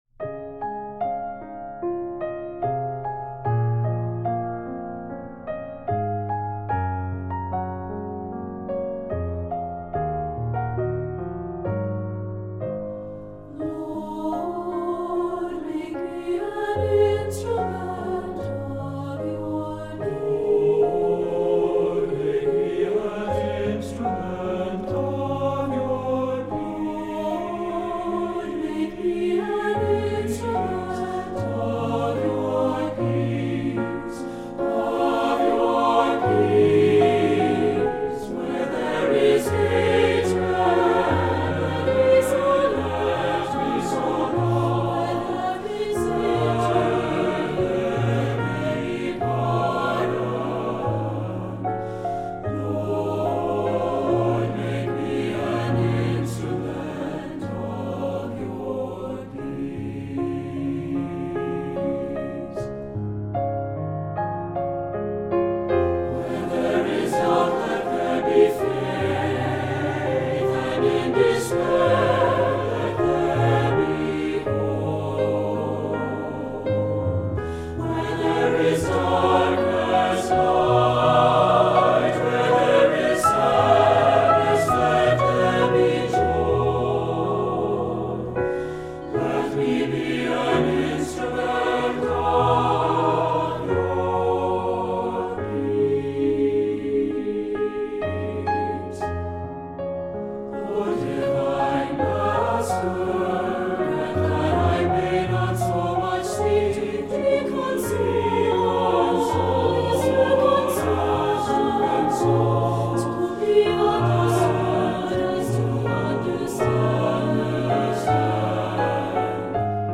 Voicing: SATB and Piano